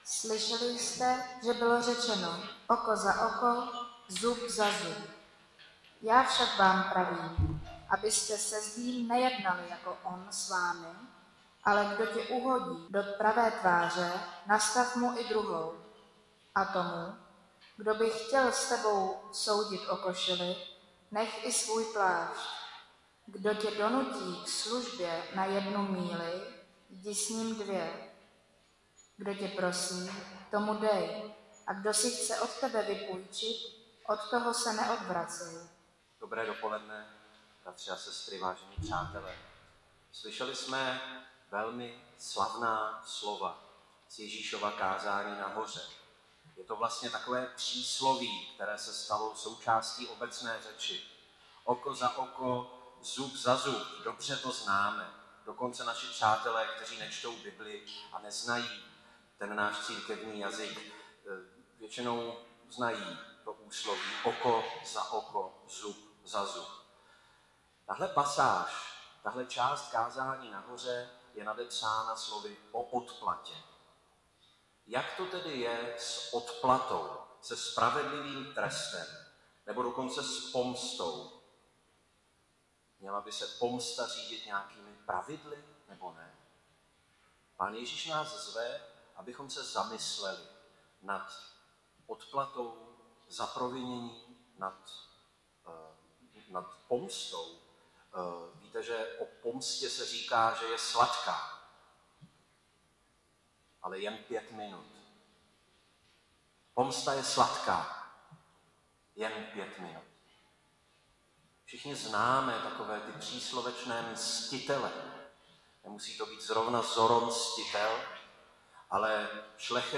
Nedělní kázání 24.10.2021 – O odplatě